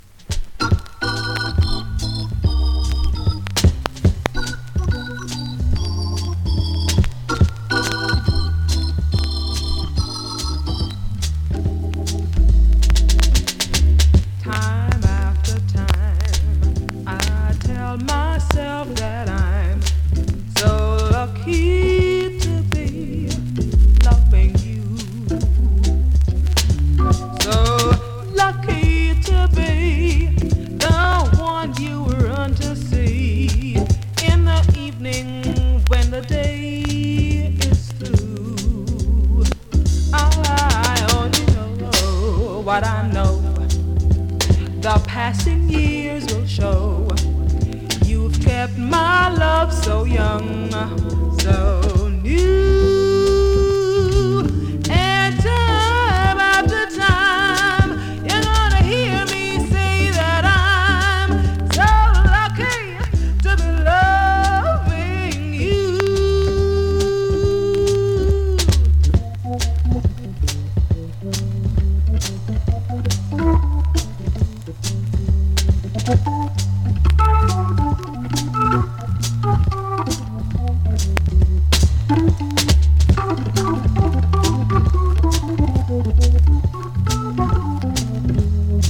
2026!! NEW IN!SKA〜REGGAE
スリキズ、ノイズ比較的少なめで